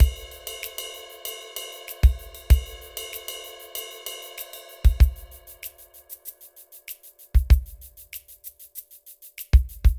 funky_pop_drums_03.aif